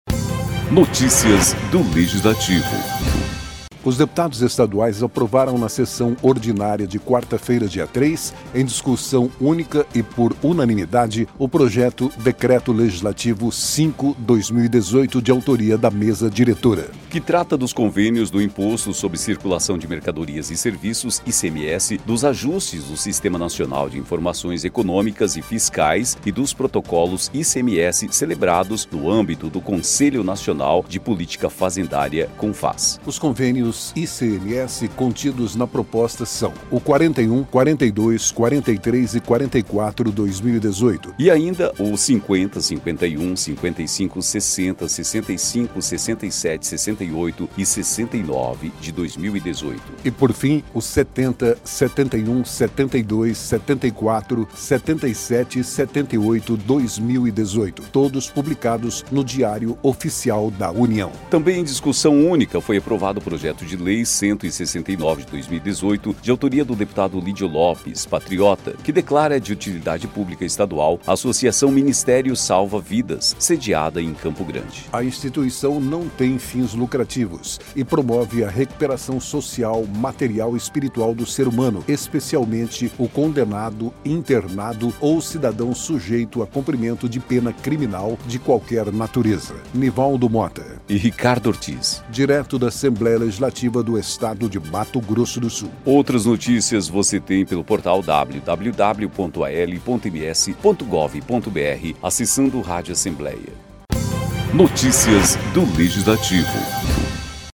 Os deputados estaduais aprovaram na sessão ordinária desta quarta-feira (3), em discussão única e por unanimidade, o Projeto de Decreto Legislativo 5/2018, de autoria da Mesa Diretora, que trata dos Convênios do Imposto sobre Circulação de Mercadorias e Serviços (ICMS), dos ajustes do Sistema Nacional de Informações Econômicas e Fiscais (Sinief) e dos Protocolos ICMS, celebrados no âmbito do Conselho Nacional de Política Fazendária (Confaz).